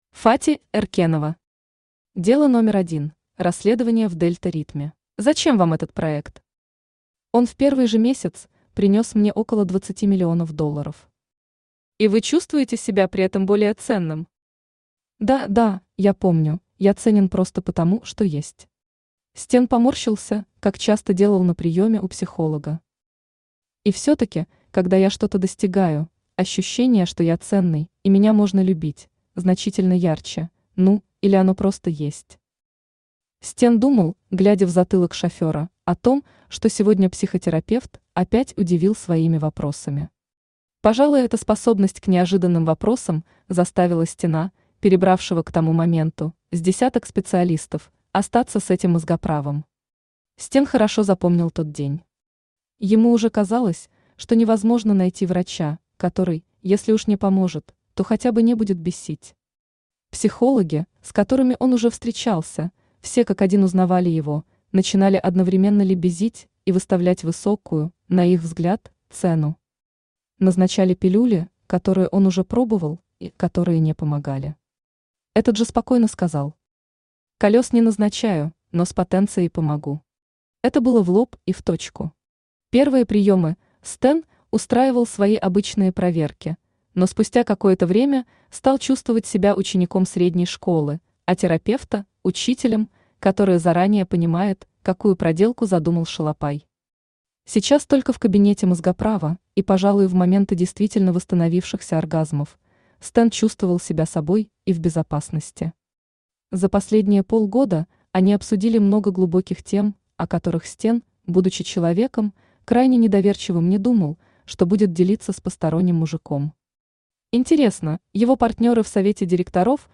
Aудиокнига Дело номер один: расследование в дельта ритме Автор Фати Эркенова Читает аудиокнигу Авточтец ЛитРес.